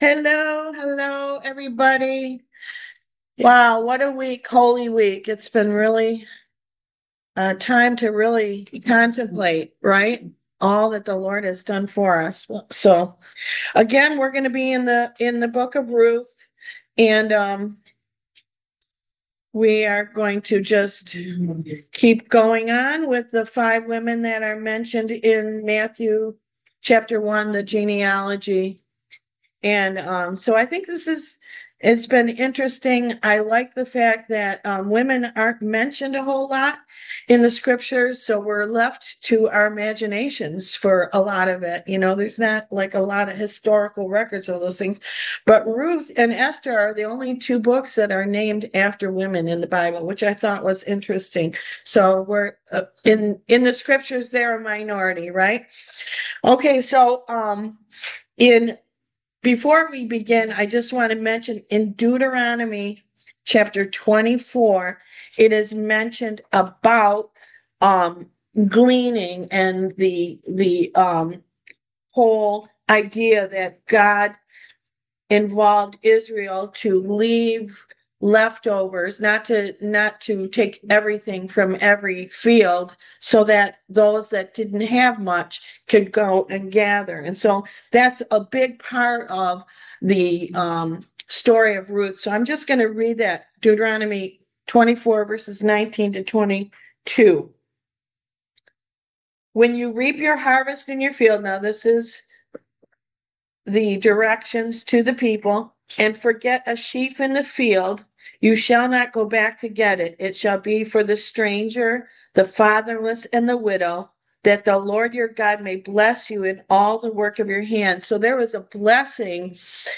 Service Type: Why Jesus Class